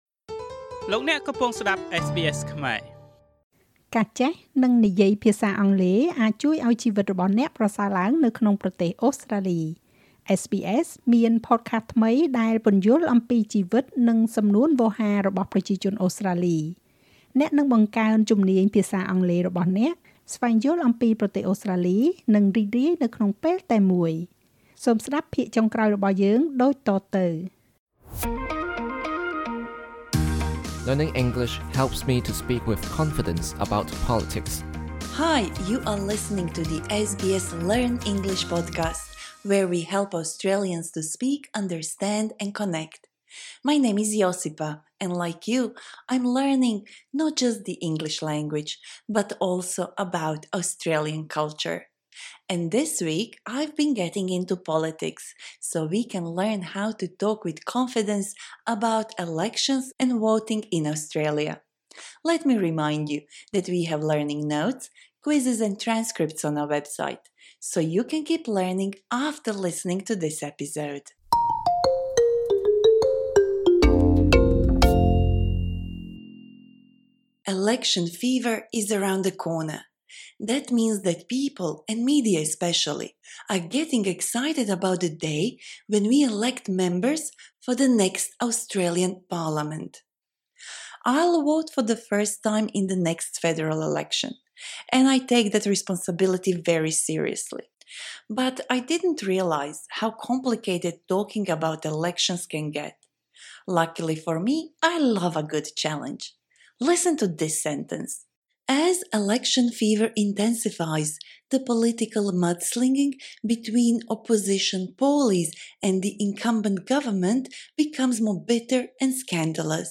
SBS Learn English ភាគទី9៖ បទសន្ទនាអំពីការបោះឆ្នោតនៅក្នុងប្រទេសអូស្រ្តាលី